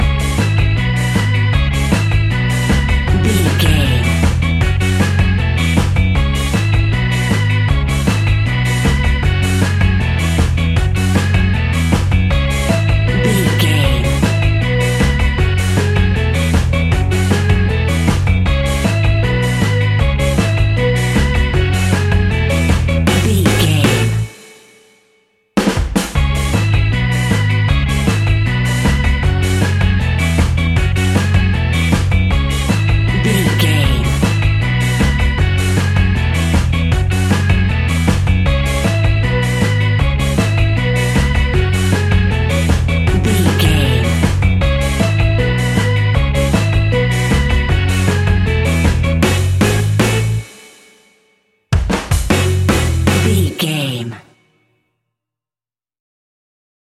Classic reggae music with that skank bounce reggae feeling.
Aeolian/Minor
F#
reggae instrumentals
laid back
chilled
off beat
drums
skank guitar
hammond organ
percussion
horns